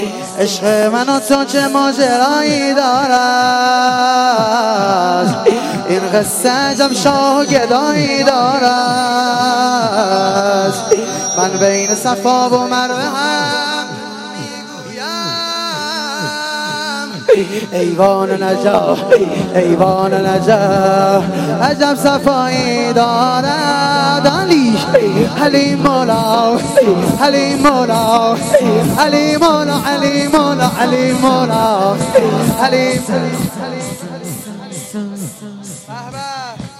رجز و ذکر | عشق منو تو چه ماجرایی دارد
میلاد امام علی (علیه السلام)